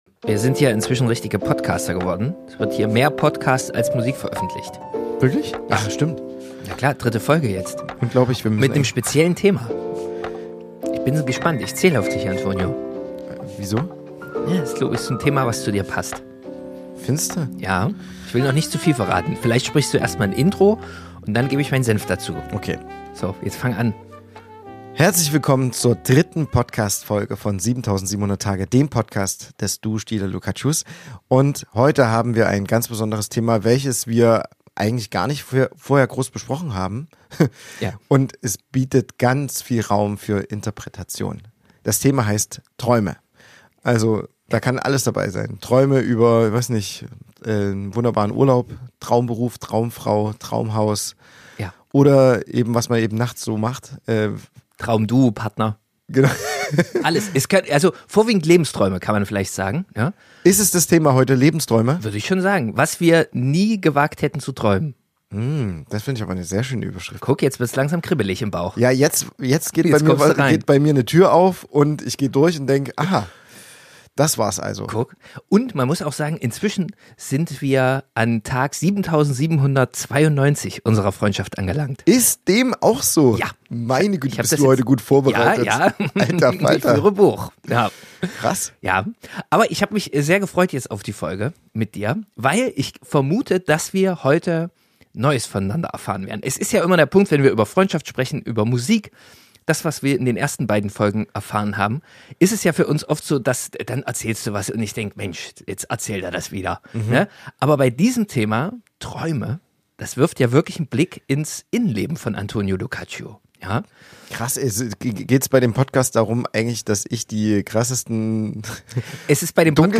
Zwischen Batman, Max Raabe, unterirdischen Wiesel-Fantasien und dem Traum vom Fliegen sprechen zwei Freunde über alles, was man sich nie zu sagen traut – außer in einem Podcast, bei laufendem Mikrofon und schlaftrunkenem Selbstbewusstsein. Ein Gespräch über die großen und kleinen Träume unseres Lebens.